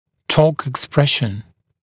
[tɔːk ɪk’spreʃn] [ek-][то:к ик’спрэшн] [эк-]реализация торка, отработка торка, выражение торка